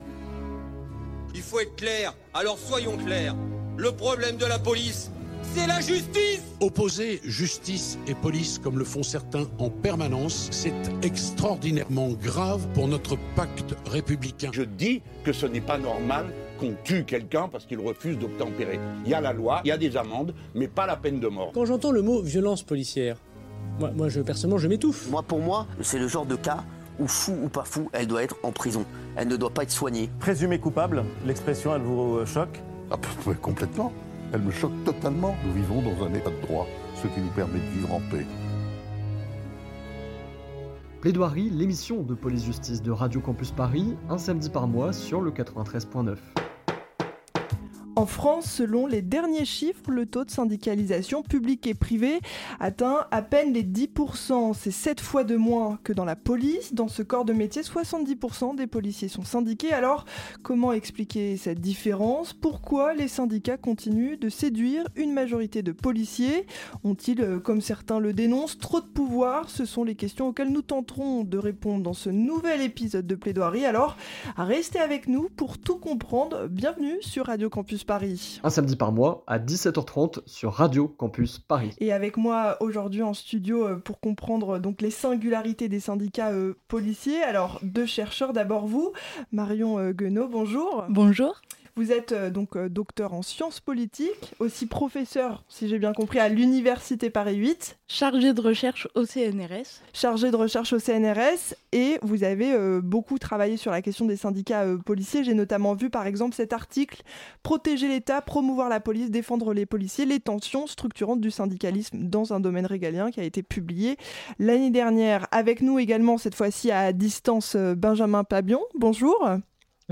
Partager Type Entretien Société vendredi 8 novembre 2024 Lire Pause Télécharger Alors que le taux de syndicalisation, public et privé confondus, atteint à peine les 10%, la police fait figure d'exception.
L'émission a été pré-enregistrée dans un studio de Radio Campus Paris.